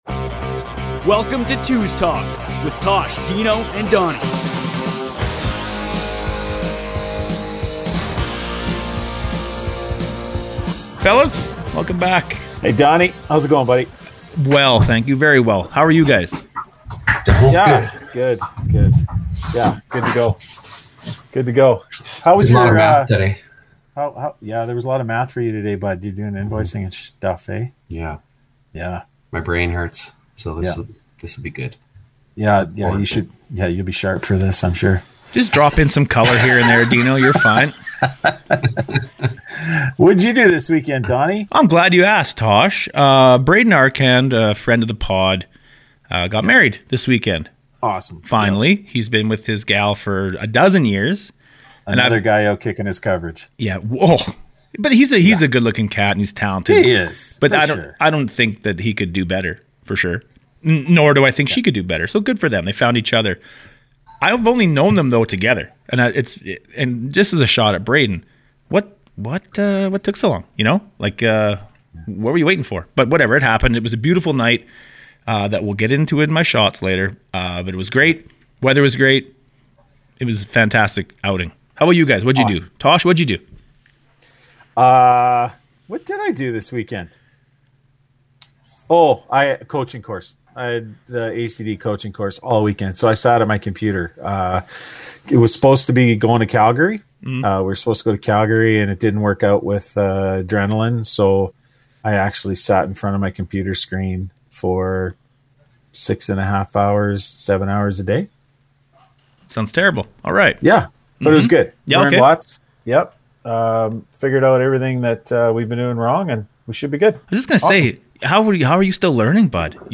Today the fellas sit down with three members of Team Canada as they prepare for the Pan Am games in Argentina.